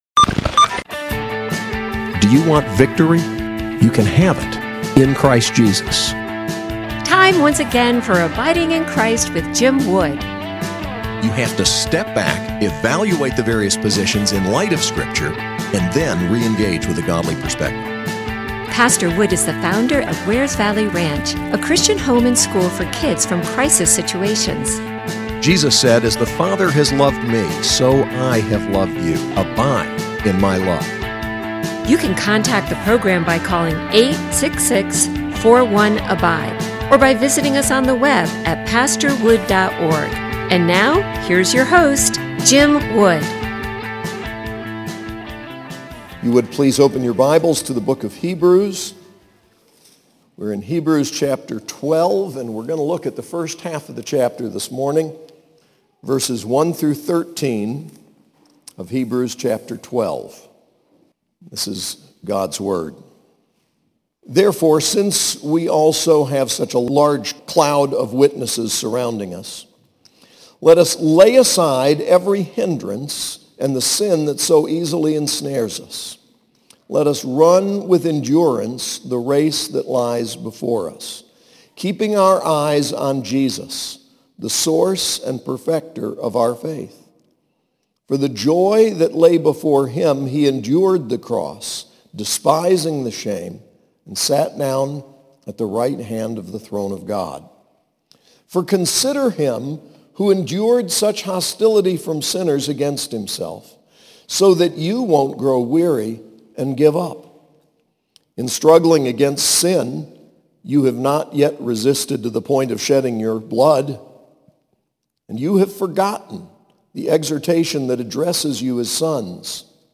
SAS Chapel: Hebrews 12:1-13